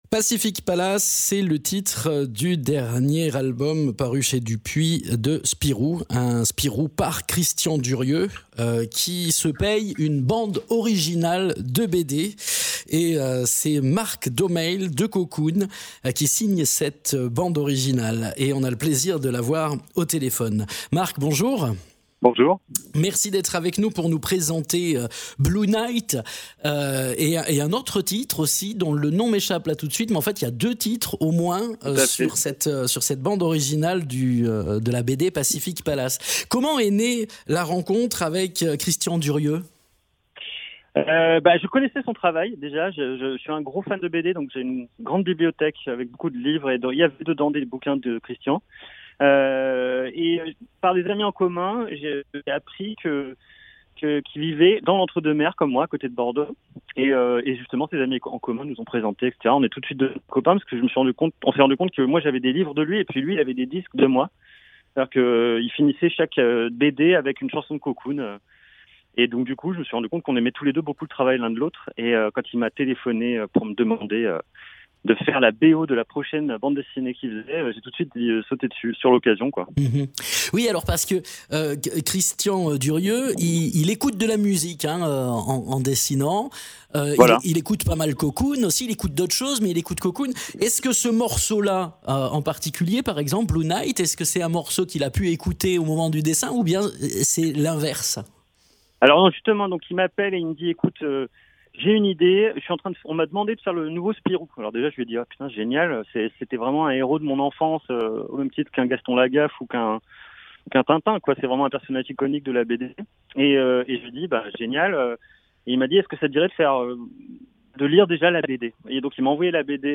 Interviews
Invité(s) : Mark Daumail du groupe Cocoon